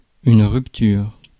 Ääntäminen
IPA : /ˌɪntəˈɹʌpʃən/